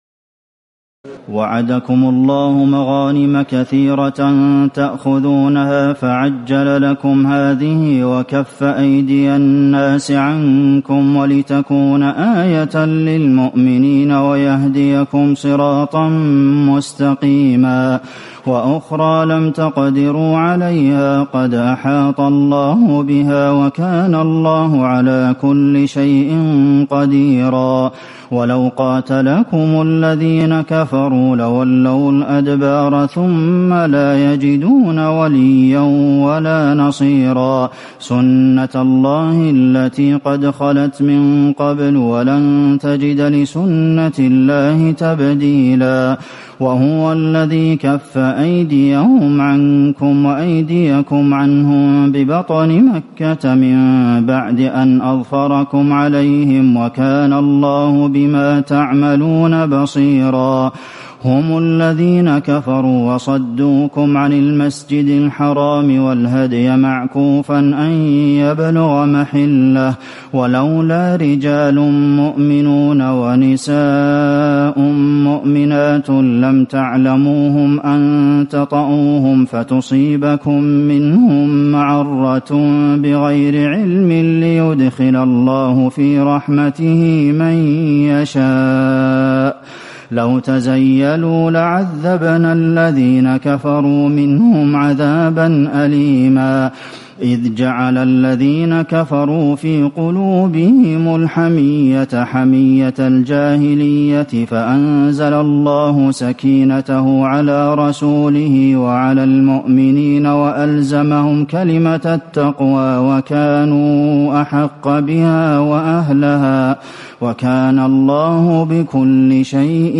تراويح ليلة 25 رمضان 1437هـ من سور الفتح (20-29) الحجرات و ق و الذاريات (1-23) Taraweeh 25 st night Ramadan 1437H from Surah Al-Fath and Al-Hujuraat and Qaaf and Adh-Dhaariyat > تراويح الحرم النبوي عام 1437 🕌 > التراويح - تلاوات الحرمين